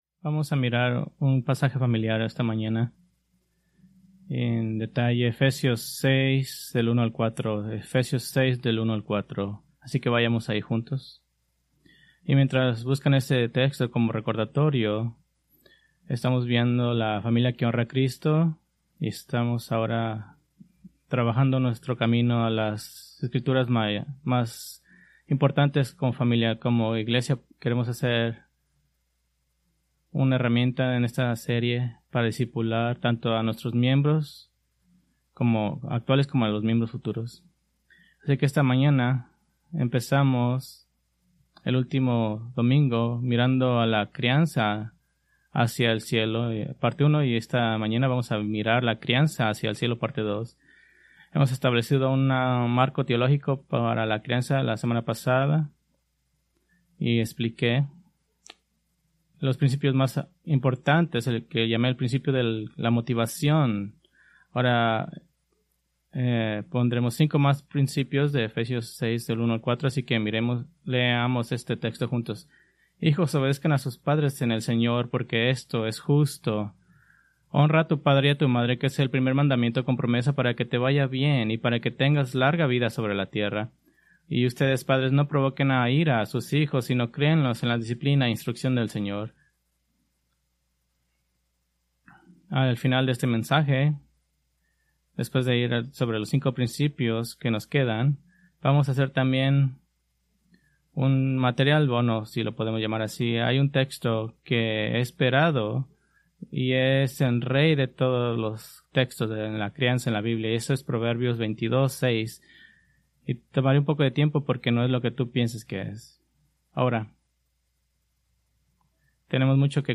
Preached August 24, 2025 from Escrituras seleccionadas